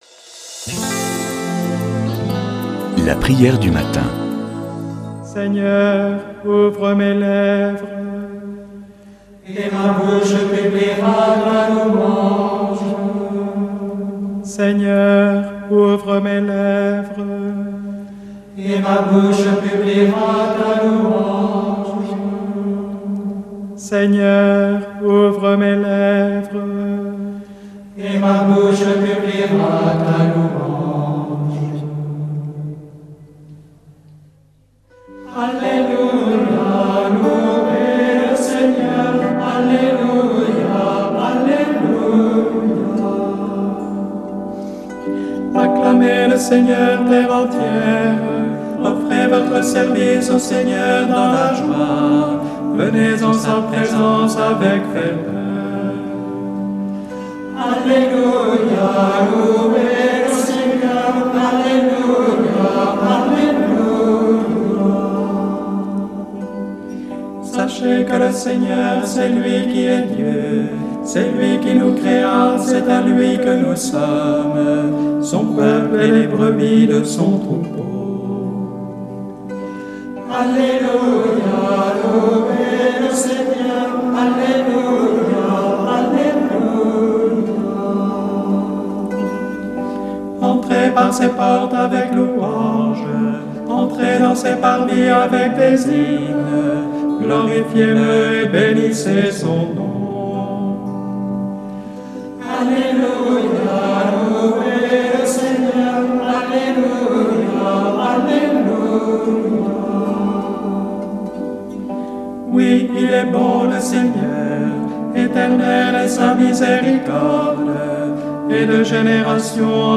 Prière du matin
ABBAYE D EN CALCAT